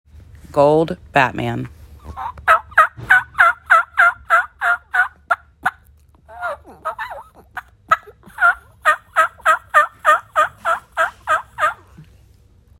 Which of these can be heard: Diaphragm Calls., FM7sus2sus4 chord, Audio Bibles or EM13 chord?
Diaphragm Calls.